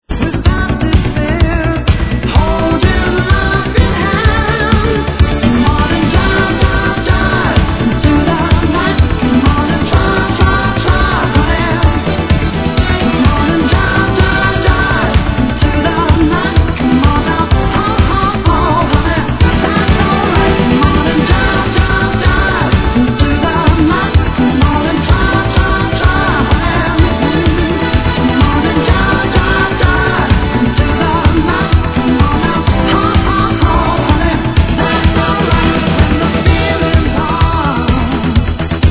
Tag       OTHER ROCK/POPS/AOR